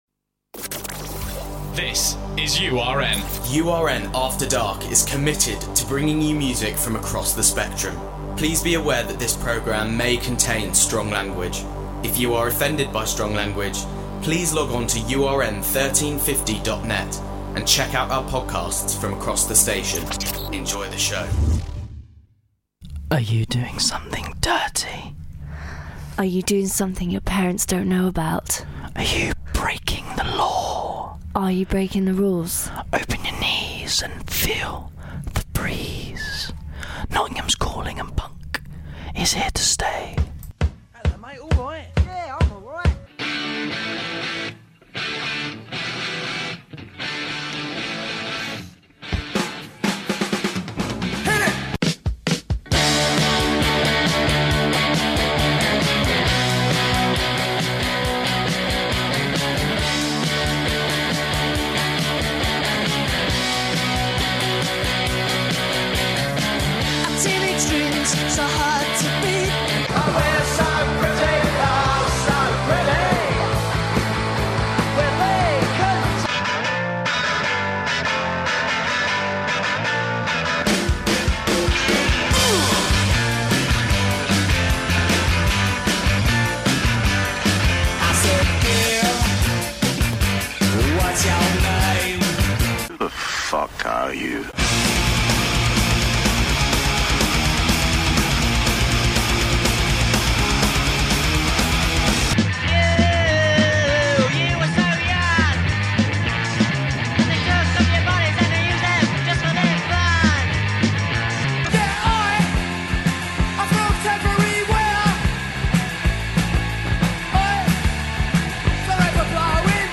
Notts Calling- URN's Own Punk Rock Show